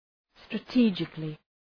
Προφορά
{strə’ti:dʒıklı}